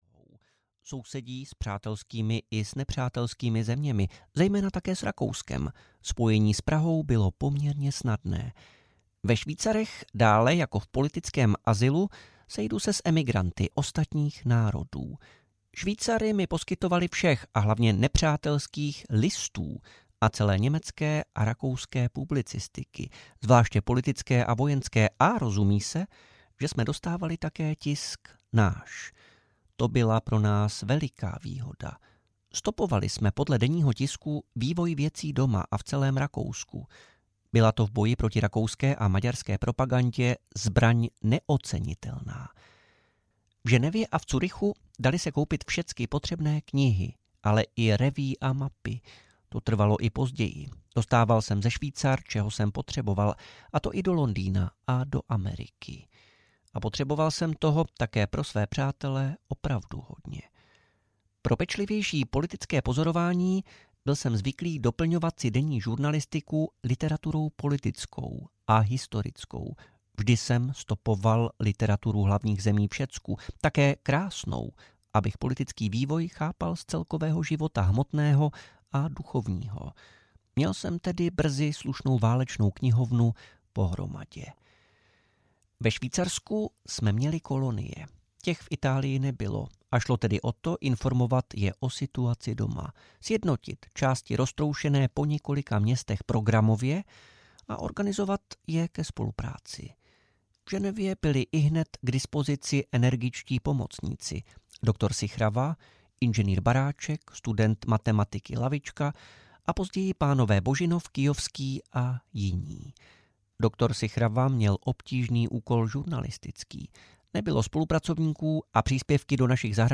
Světová revoluce – část 3 audiokniha
Ukázka z knihy